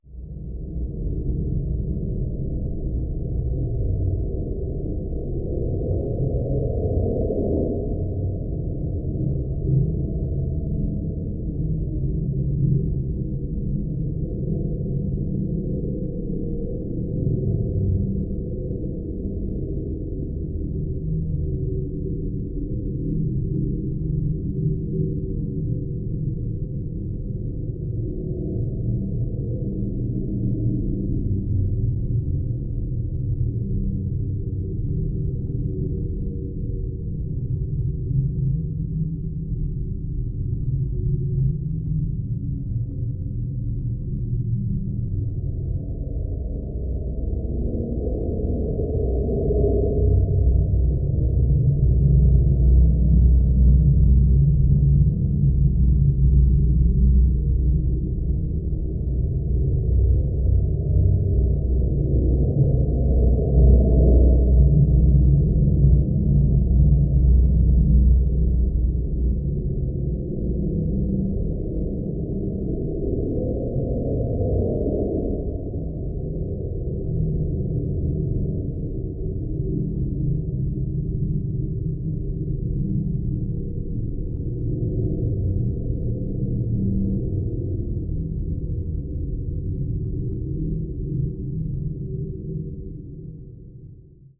Туманные звуки в крепости ночью